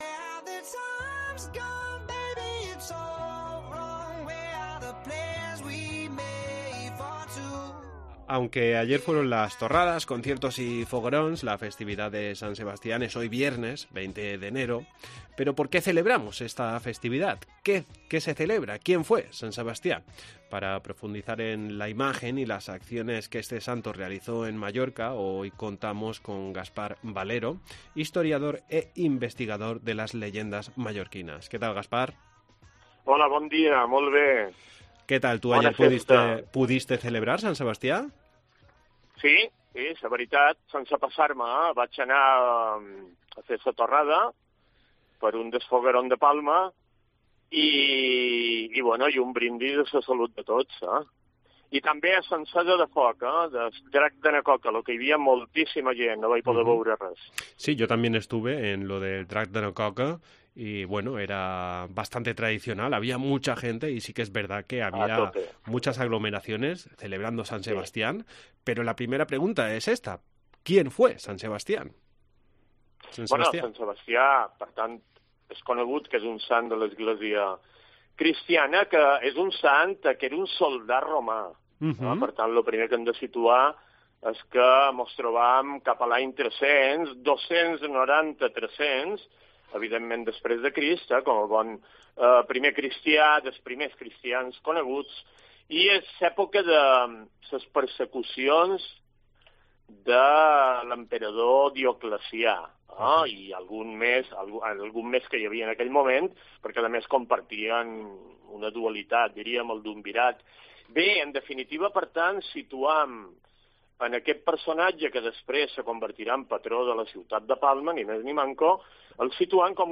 Hablamos con él para conocer mejor la figura de Sant Sebastià, patrón de Palma.